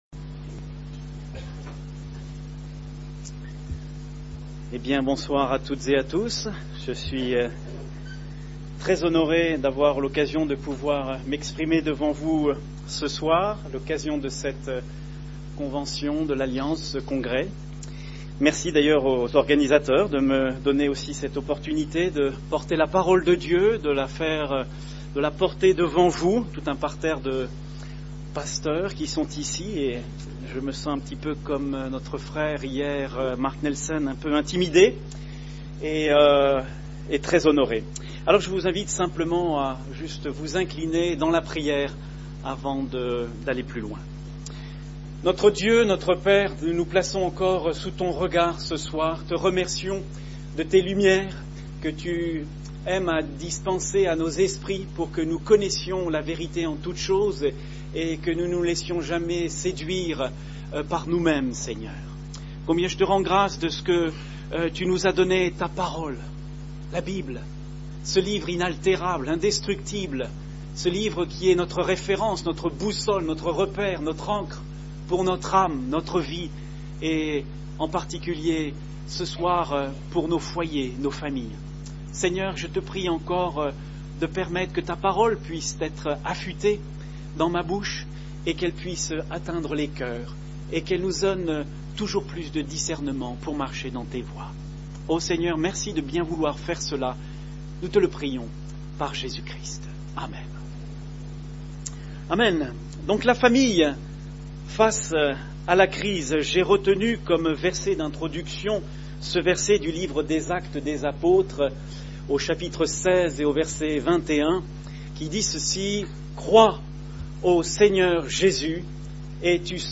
Nous n’avons peut-être pas toutes les solutions pour sortir de la crise économique, mais Dieu nous en donne pour les crises plus personnelles. Nous vous invitons à écouter les messages qui furent donnés au congrès 2009 de l’Alliance Baptiste de France.